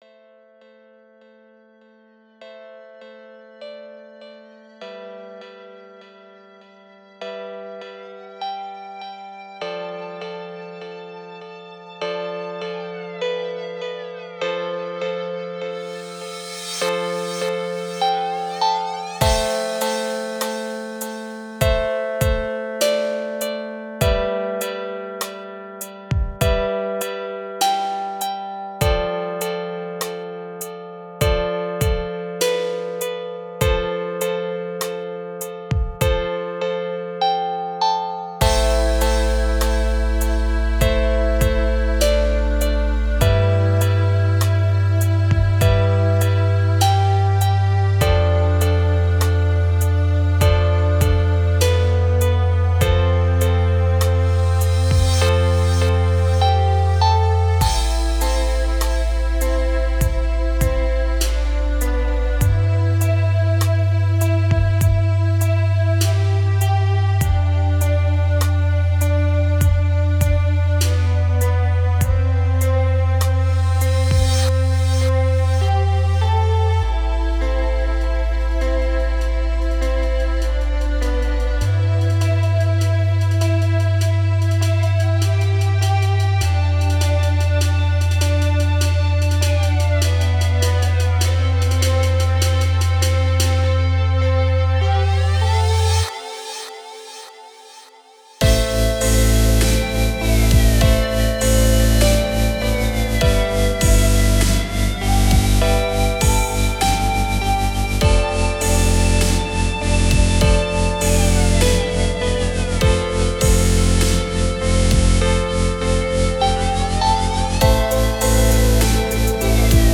это трек в жанре электроника с элементами синти-попа